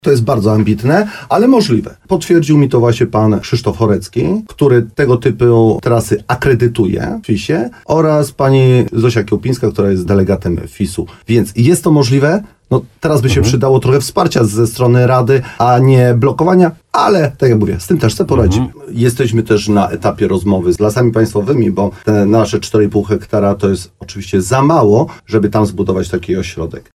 Wójt Mirosław Cichorz w programie Słowo za Słowo na antenie RDN Nowy Sącz, przekonuje, że jest to jak najbardziej realne i powołuje się na głos przedstawicieli świata narciarskiego, z którymi spotkał się w tej sprawie w terenie.